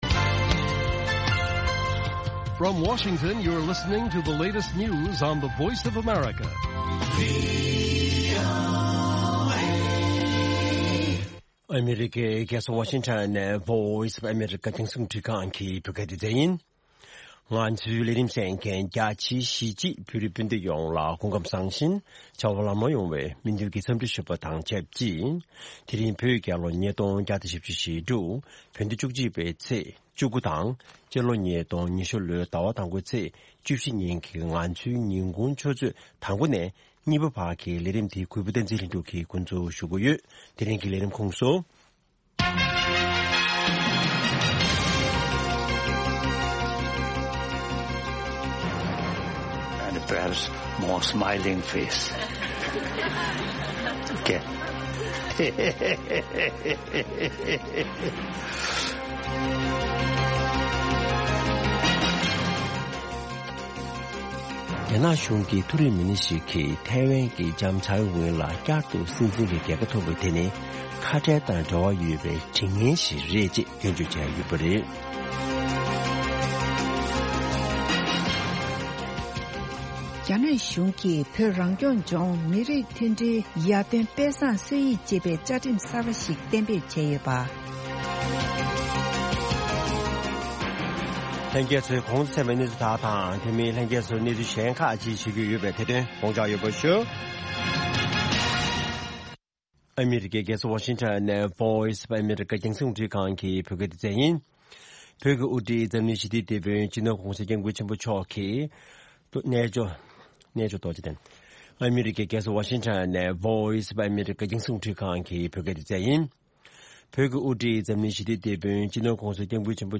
Afternoon Show Broadcast daily at 12:00 Noon Tibet time, the Lunchtime Show presents a regional and world news update, followed by a compilation of the best correspondent reports and feature stories from the last two shows. An excellent program for catching up on the latest news and hearing reports and programs you may have missed in the morning or the previous night.